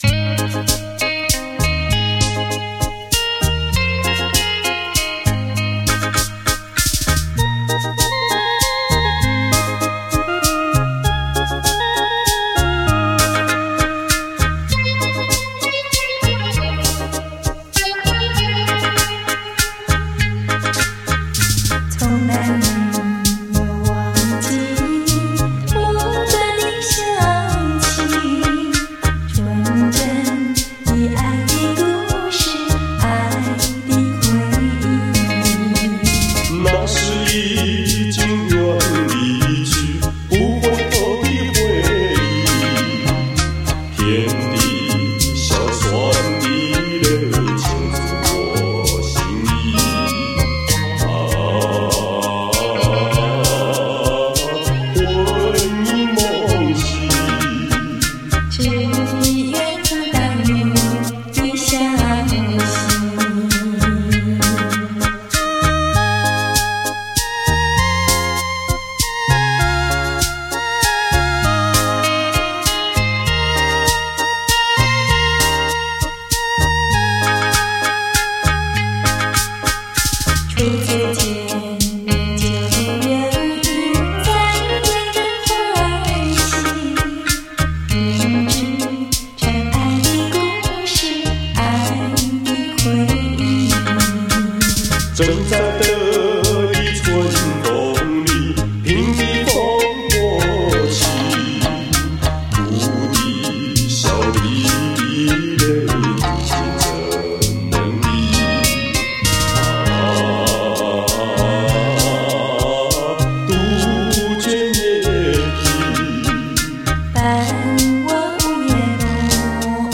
原本都是独唱
更显得很有浪漫的气氛